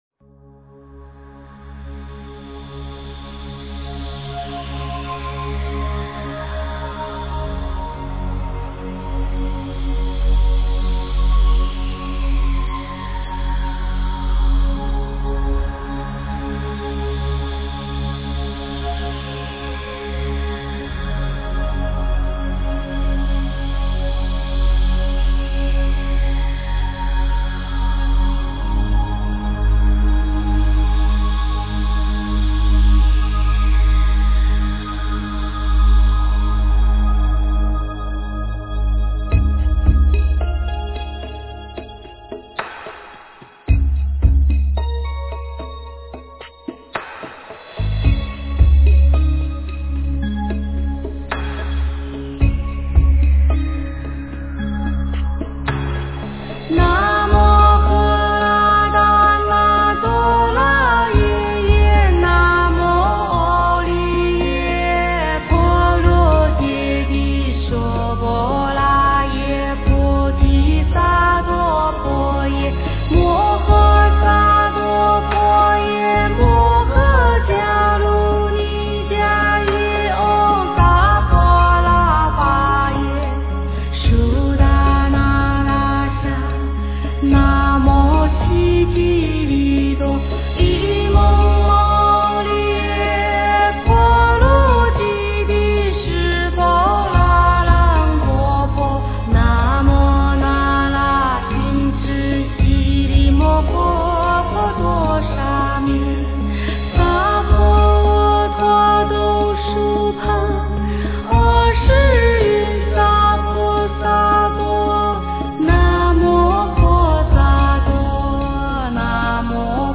诵经
佛音 诵经 佛教音乐 返回列表 上一篇： 《华严经》06卷 下一篇： 大悲咒 相关文章 药师经 5--佚名 药师经 5--佚名...